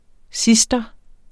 Udtale [ ˈsisdʌ ]